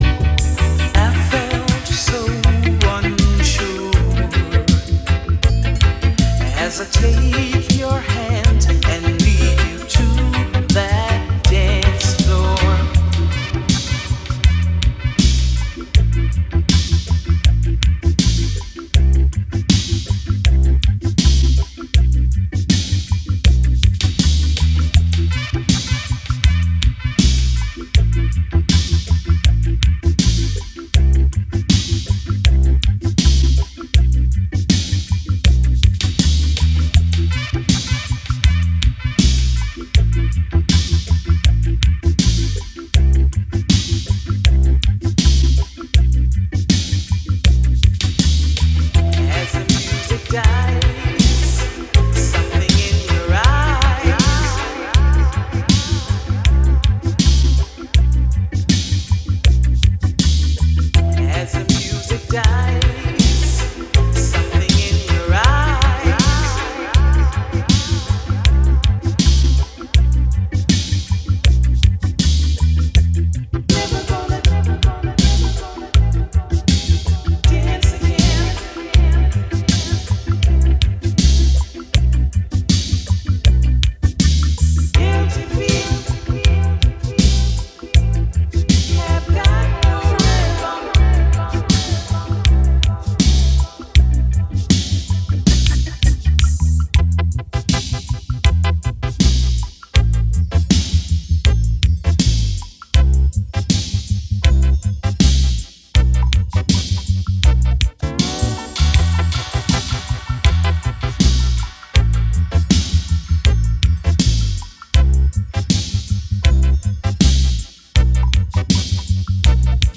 ジャンル(スタイル) LOVERS ROCK / REGGAE